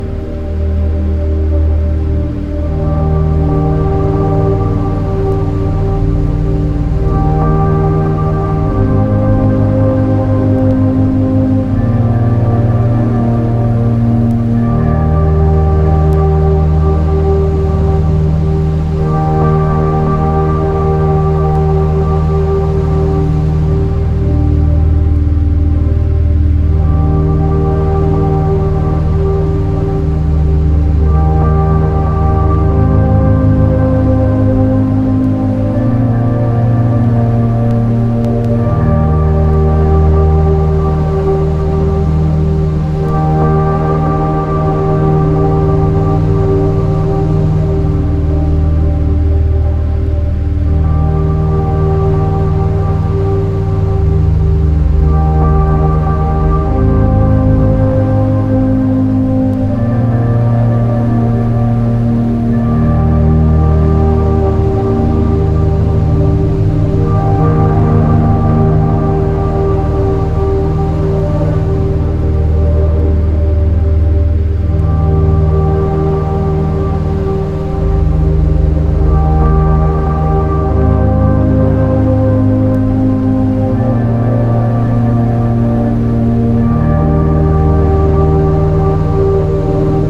Ambient Electronica / Synth Soundscape